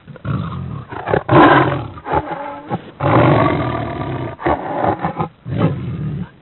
На этой странице собраны звуки гепарда в естественной среде обитания: от грозного рыка до нежного мурлыканья детенышей.
Громкий рык гепарда в вольере